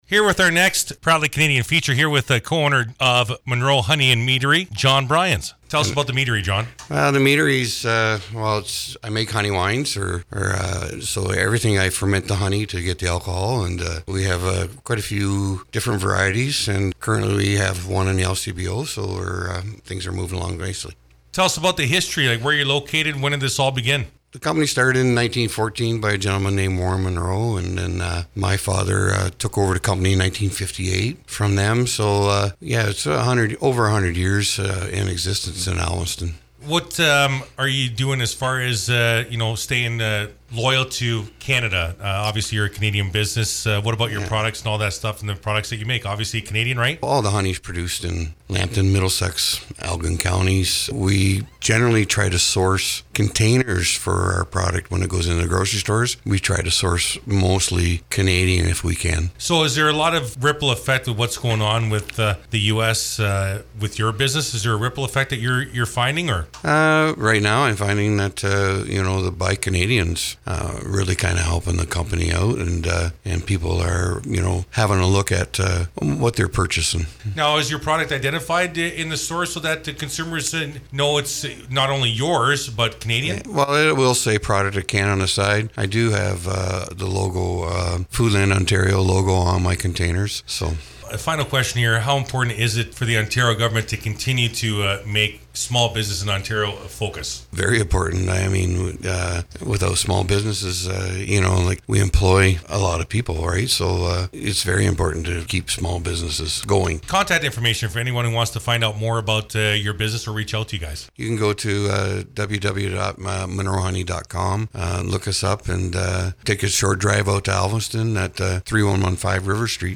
Here is the complete interview